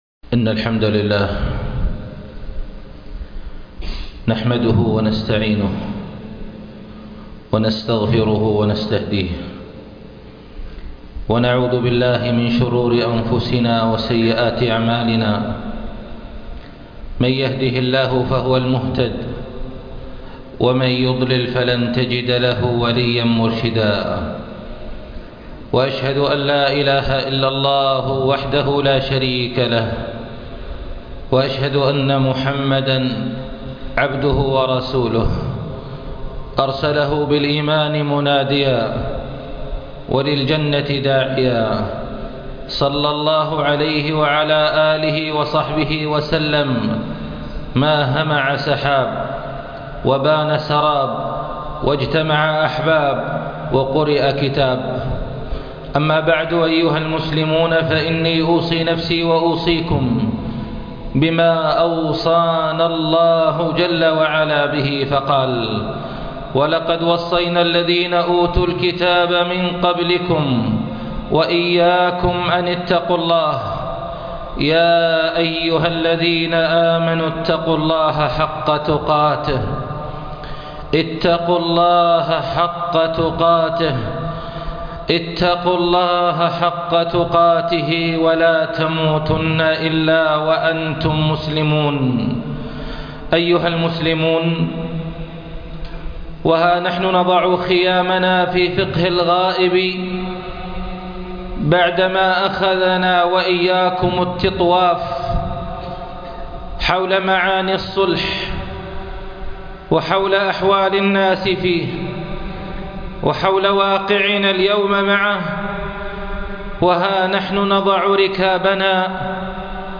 فقه الغائب 3 ( خطب الجمعة